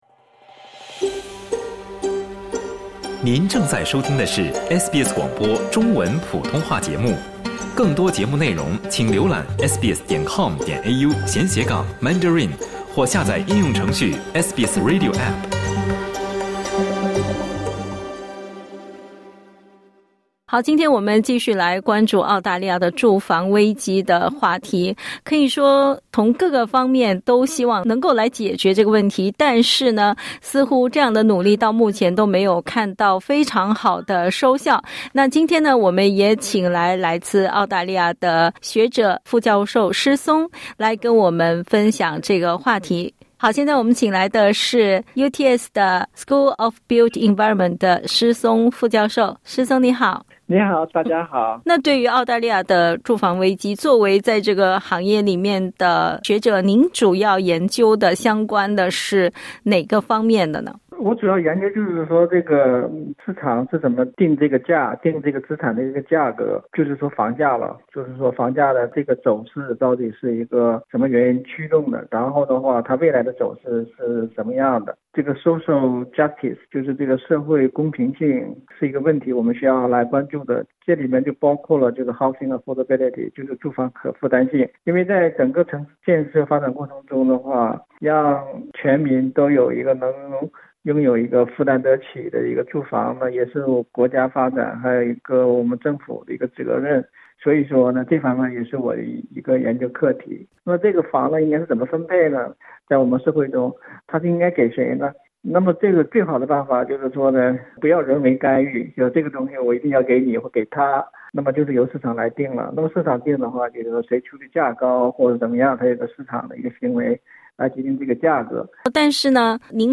（点击音频收听详细采访） 澳大利亚住房和城市研究所（AHURI）近日发布的报告指出，五分之三的租房者预计自己永远无法拥有自己的房产。